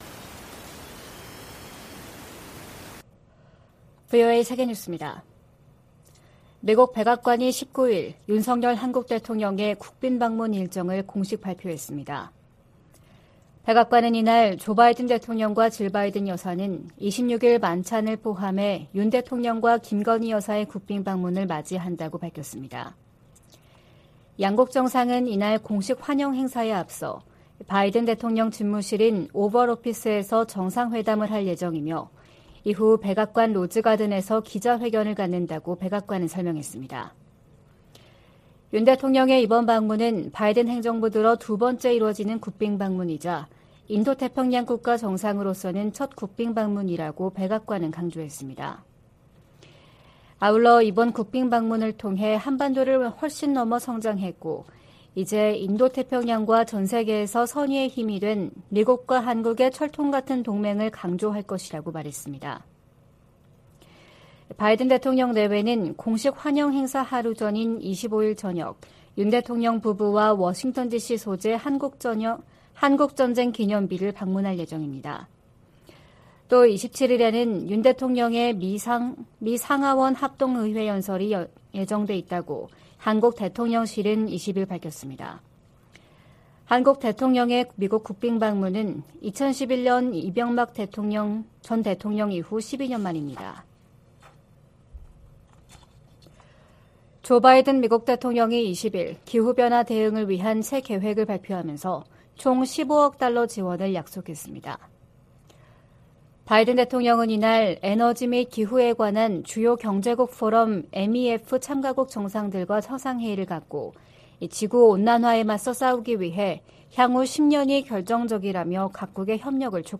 VOA 한국어 '출발 뉴스 쇼', 2023년 4월 21일 방송입니다. 백악관은 윤석열 한국 대통령의 방미 일정을 밝히고, 26일 오벌 오피스에서 조 바이든 대통령과 회담한다고 발표했습니다. 미 국무부는 북한의 불법적인 대량살상무기(WMD) 개발 자금을 계속 차단할 것이라고 밝혔습니다. 윤석열 한국 대통령이 우크라이나에 대한 군사지원 가능성을 시사한 발언에 대해 러시아가 북한에 대한 첨단 무기 제공 등을 거론하며 반발하고 있습니다.